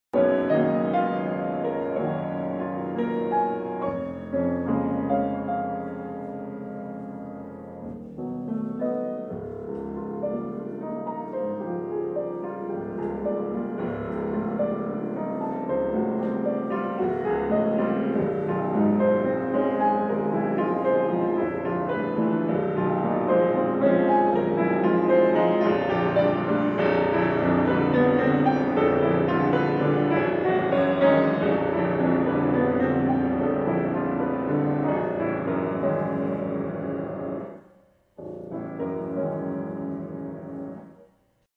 My composition, Meditation, in concert